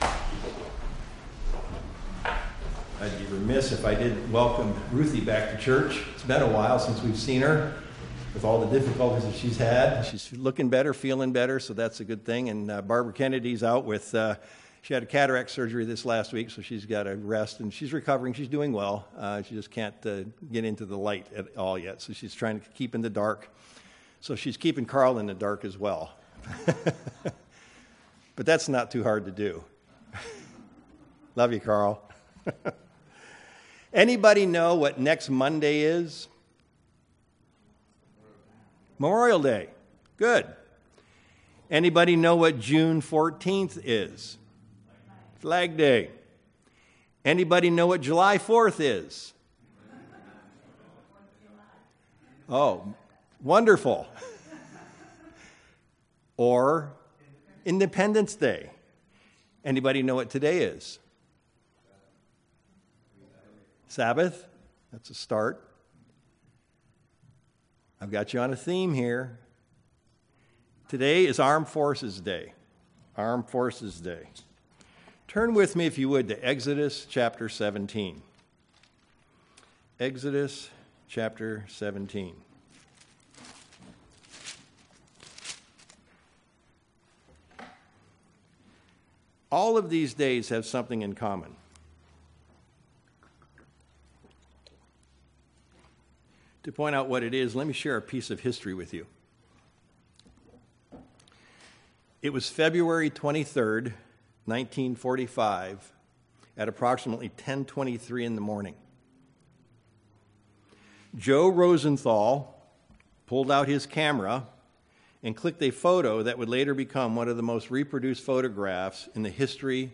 Given in Sacramento, CA
View on YouTube UCG Sermon Studying the bible?